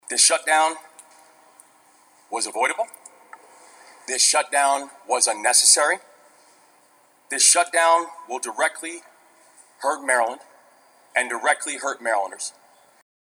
Maryland Governor Wes Moore held a news conference with state lawmakers on the first day of the federal shutdown.